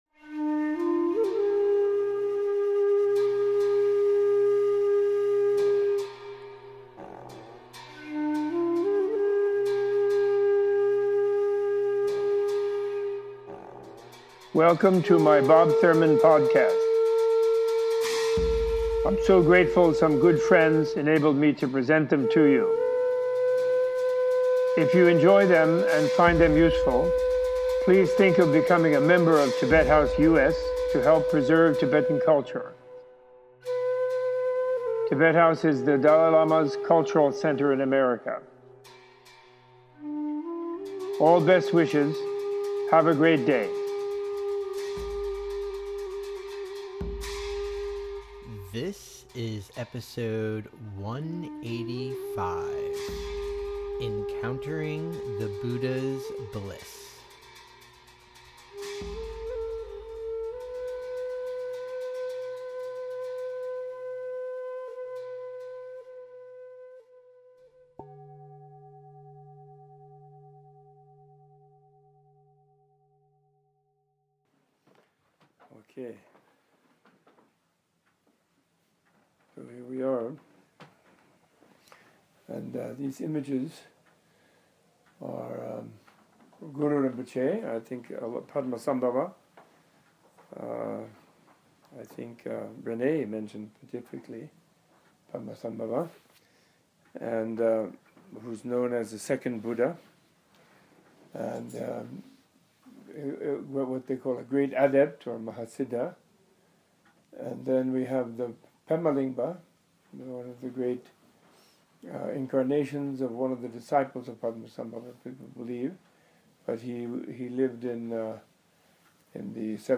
Robert A.F. Thurman in this two part podcast discusses how pilgrimage combined with study of Buddhist Philosophy can help anyone encounter the bliss at the core of his mind transformation practices and leads a guided meditation. Using the colorful depictions of the Padmasambhava, Pema Lingpa and Zhabdrung Ngawang Namgyel Professor Thurman explains the role of meditation deities in Tibetan Buddhism, the different understandings of Iconography and of Idolatry that Western and Eastern viewers hold.